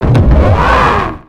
Cri de Mégapagos dans Pokémon X et Y.